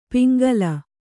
♪ piŋgala